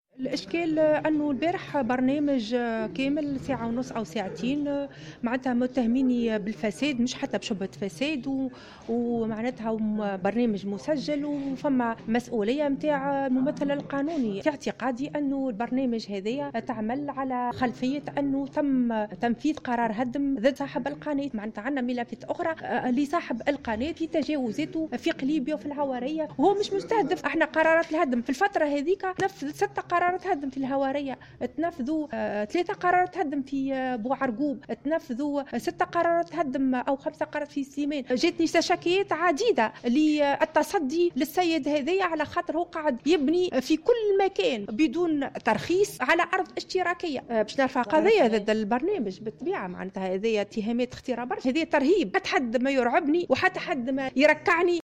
عقدت والي نابل سلوى الخياري ندوة صحفية اليوم، نفت فيها ما جاء في برنامج تلفزي على قناة خاصة، والتي اتهمتها بالفساد.